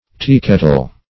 Teakettle \Tea"ket`tle\, n.